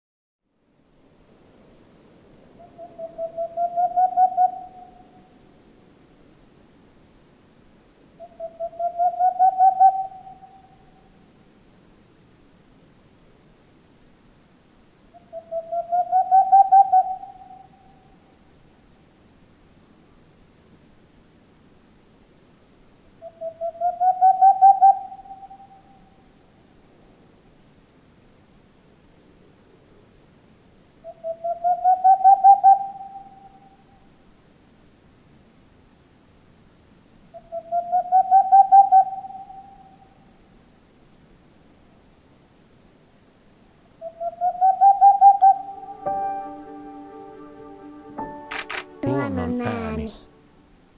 Kuuntele: Helmipöllö
Helmipöllö viihtyy suurehkoilla havumetsäalueilla, joita kuusikkokorvet, suot ja pienehköt metsäpellot pirstovat. Soidinääni nouseva ”pu pu pu pu-pu-pu-pupu” .